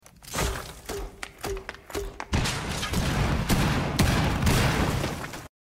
Fuse Charge Sound Button - Free Download & Play